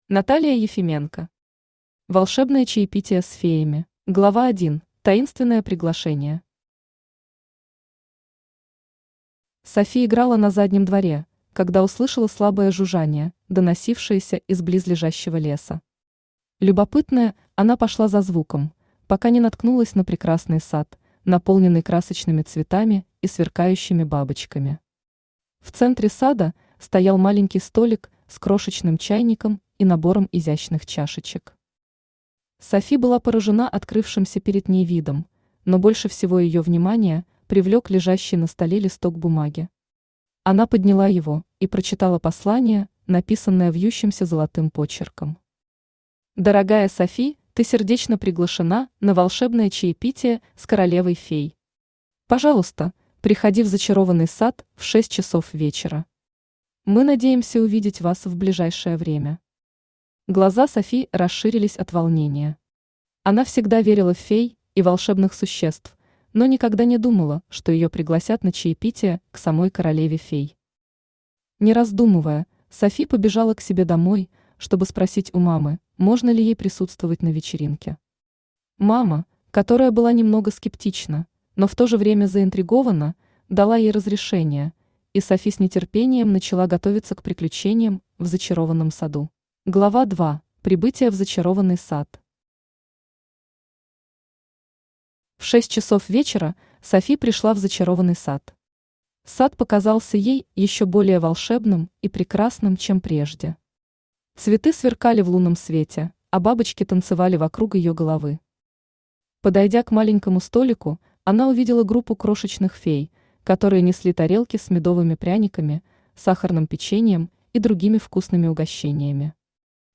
Аудиокнига Волшебное чаепитие с феями | Библиотека аудиокниг
Aудиокнига Волшебное чаепитие с феями Автор Наталия Ефименко Читает аудиокнигу Авточтец ЛитРес.